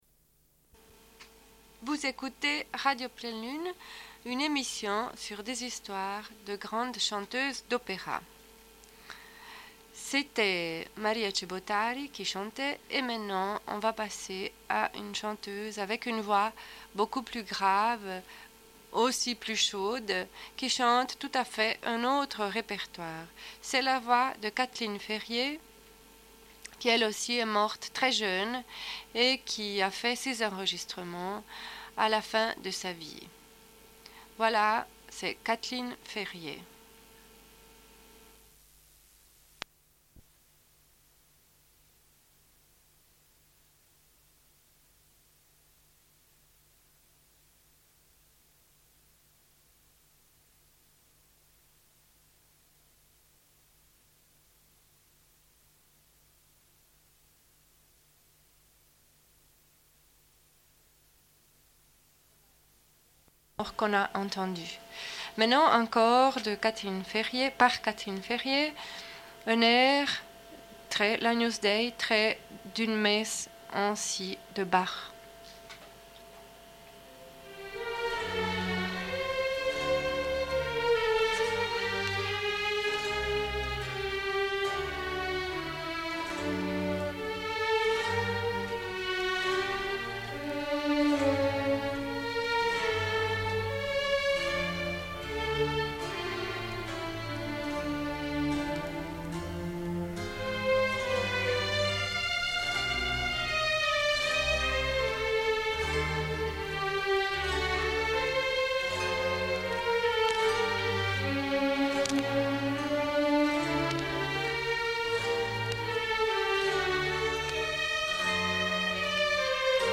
Une cassette audio, face B47:25
Alternance d'histoire et d'écoute de musique.